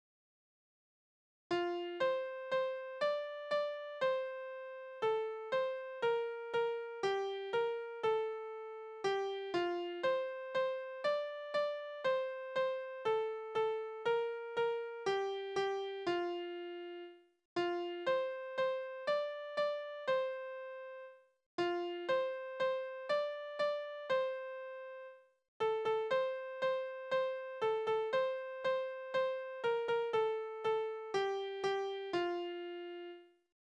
Tonart: F-Dur
Taktart: 4/4
Tonumfang: große Sexte
Besetzung: vokal